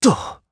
Zafir-Vox_Landing_jp.wav